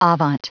Prononciation du mot avant en anglais (fichier audio)
Prononciation du mot : avant